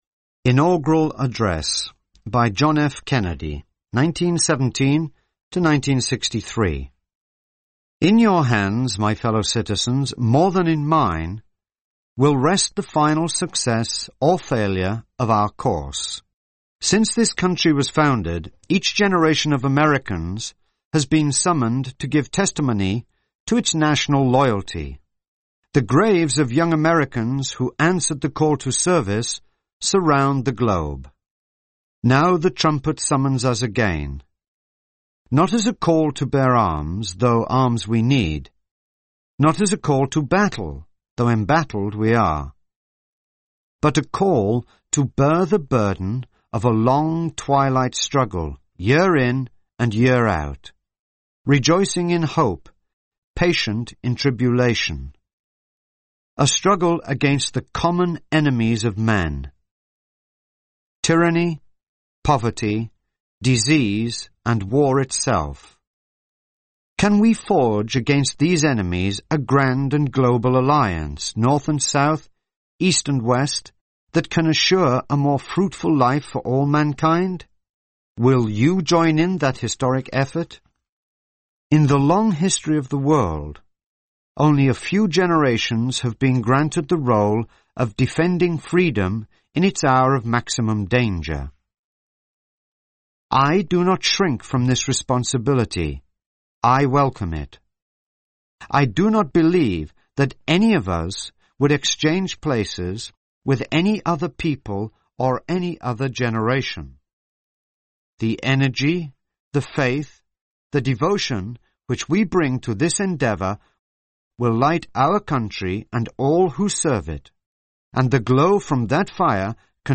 Inaugural Address
by John F. Kennedy (1917-1963)